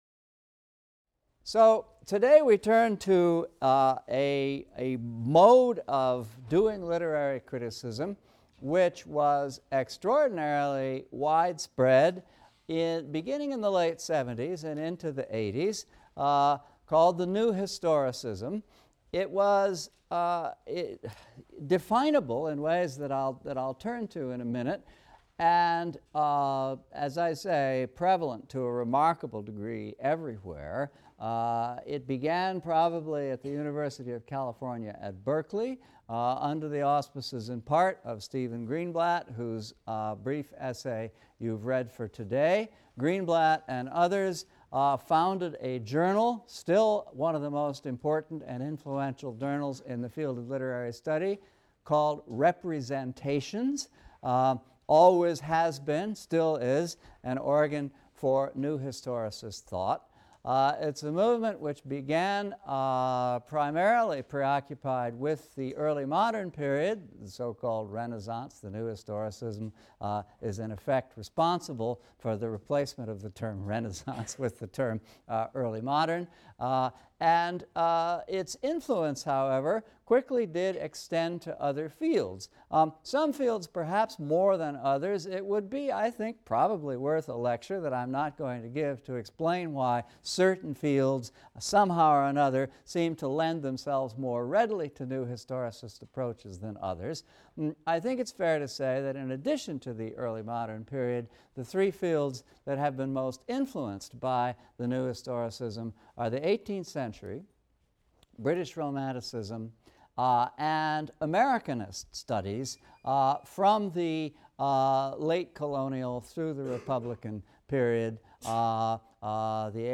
ENGL 300 - Lecture 19 - The New Historicism | Open Yale Courses